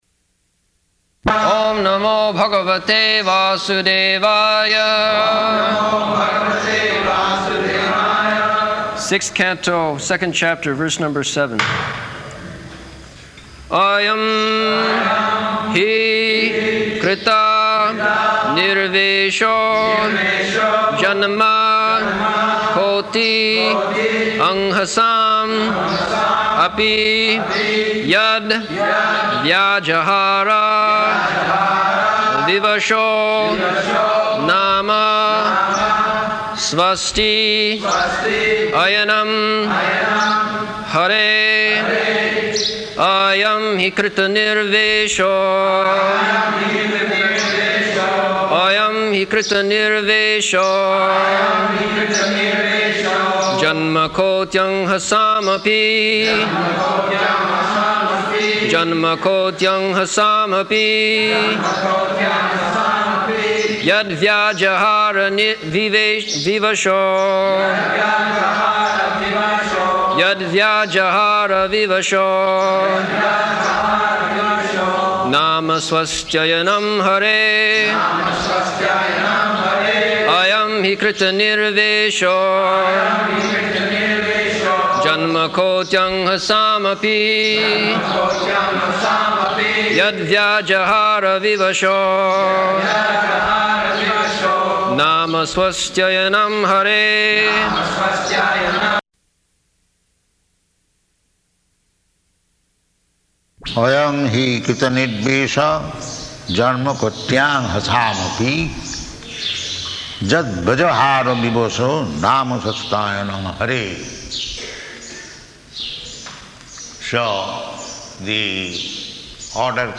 September 10th 1975 Location: Vṛndāvana Audio file
[devotees repeat] Sixth Canto, Second Chapter, verse number seven. [leads chanting of verse, etc.]